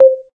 boing_pop.ogg